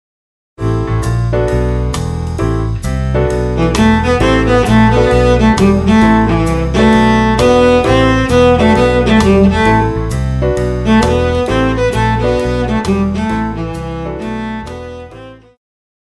Jazz,Pop
Cello
Band
Instrumental
Smooth Jazz,Rock
Only backing